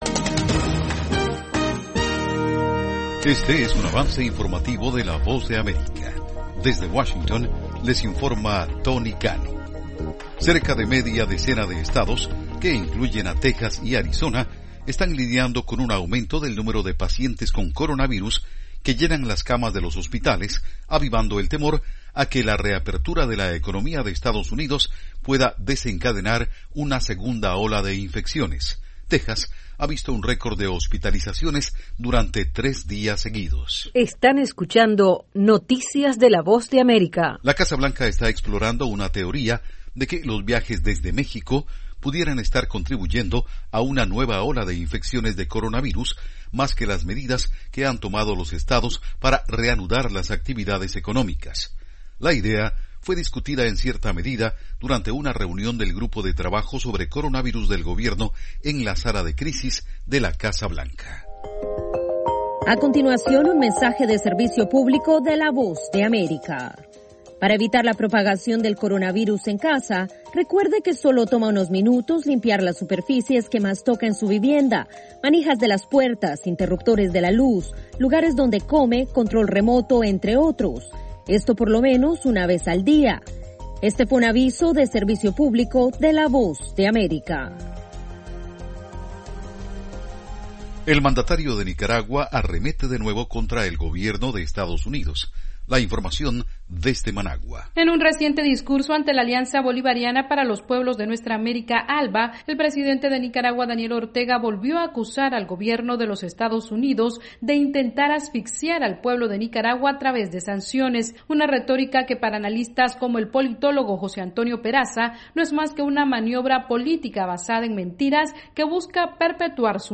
VOA: Avance Informativo - 2:00 pm